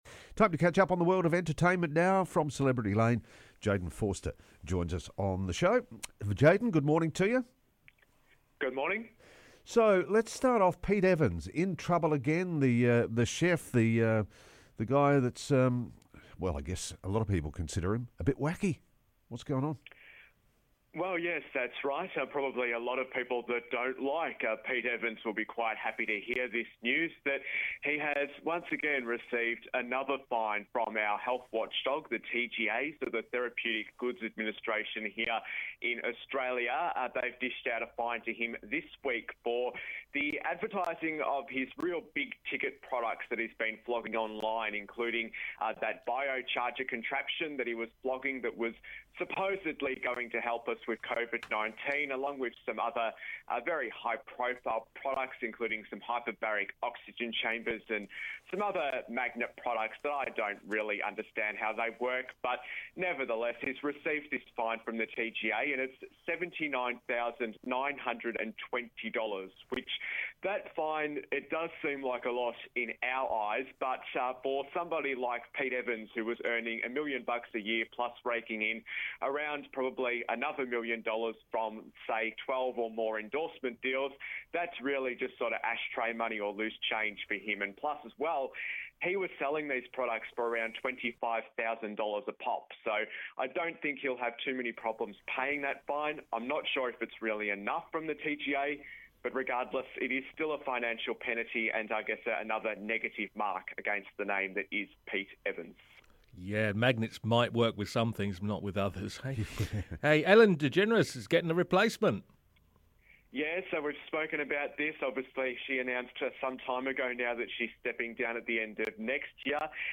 entertainment report